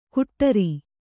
♪ kuṭṭari